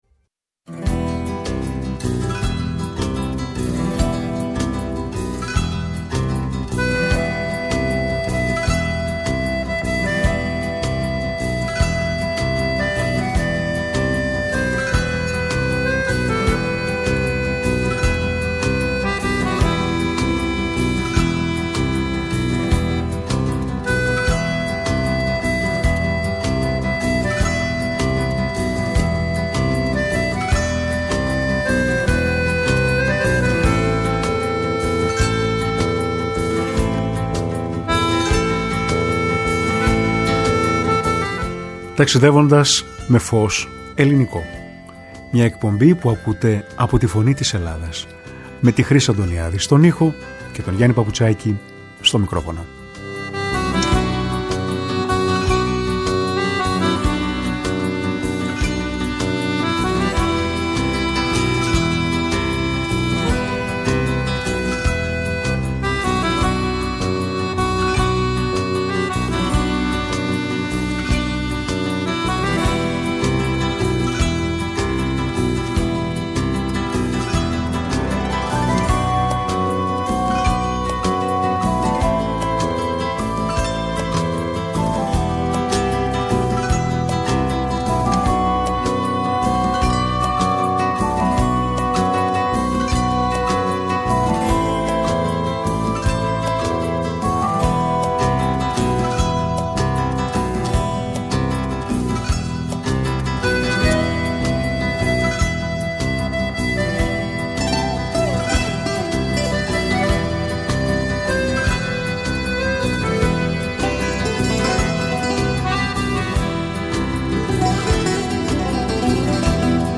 Με τραγούδια και ατάκες από ταινίες που αγαπήσαμε!